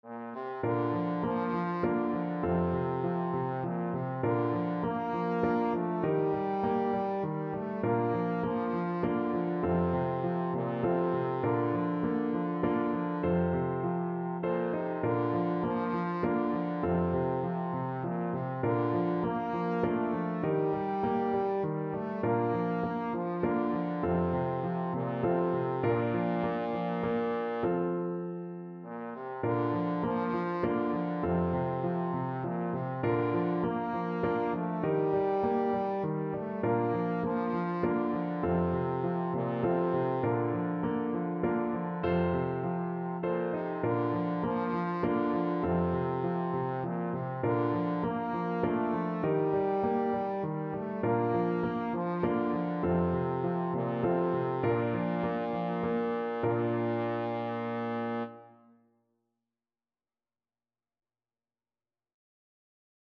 3/4 (View more 3/4 Music)
Moderato
Traditional (View more Traditional Trombone Music)